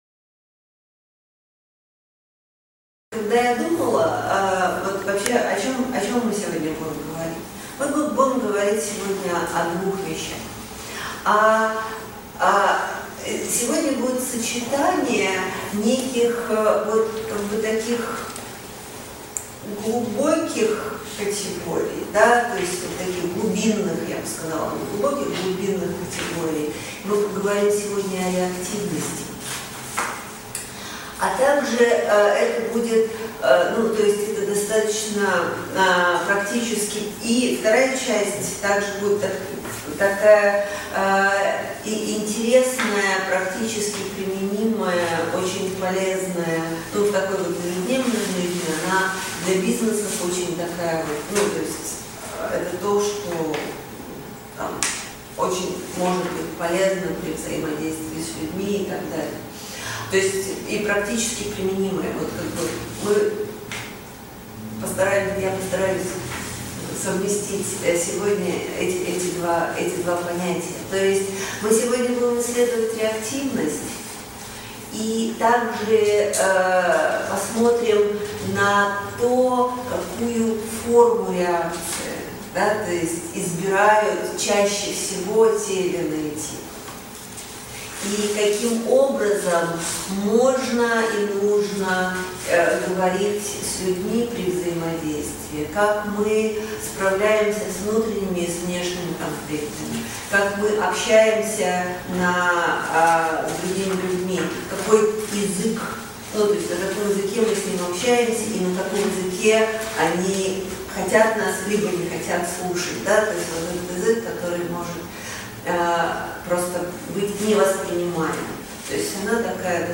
Аудиокнига Эннеаграмма и конфликты | Библиотека аудиокниг